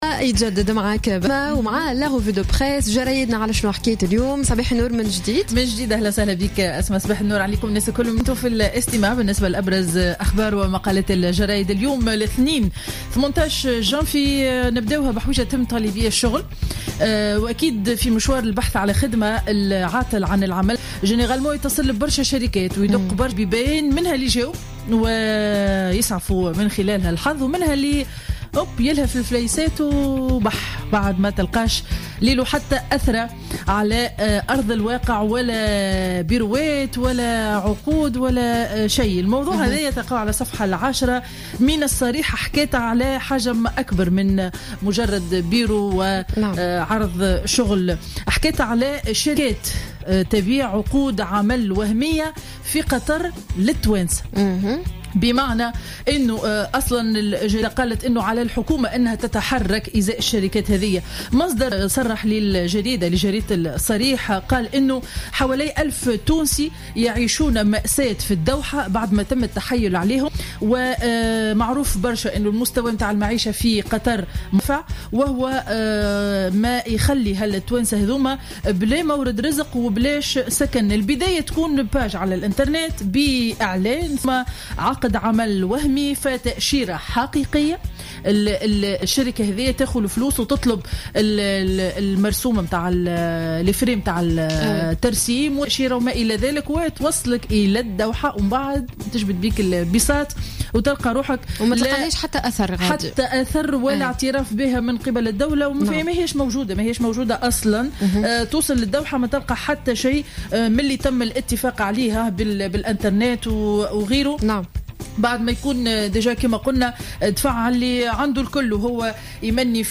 Revue de presse du lundi 18 janvier 2016